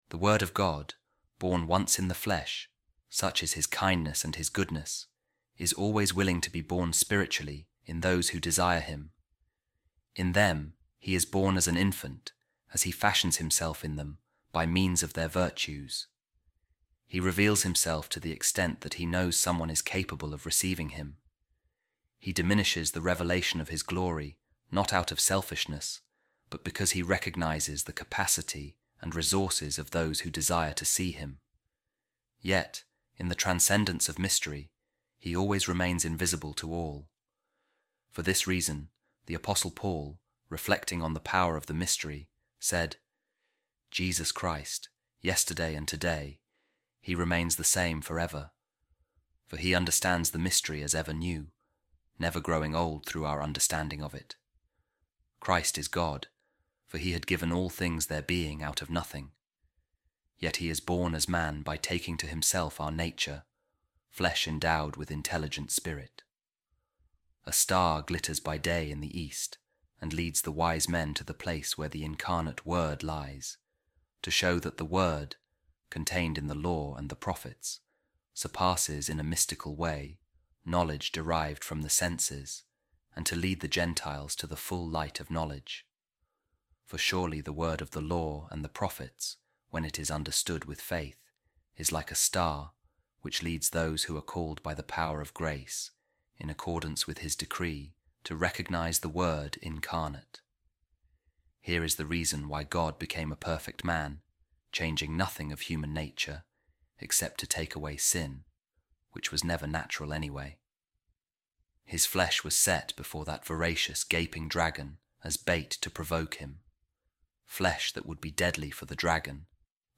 A Reading From The Five Centuries Of Saint Maximus The Confessor | The Mystery Ever New